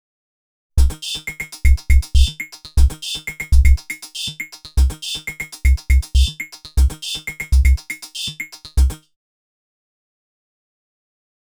Index of /90_sSampleCDs/Inspiration_Zone/rhythmic loops
05_wavesequence_3_OS.wav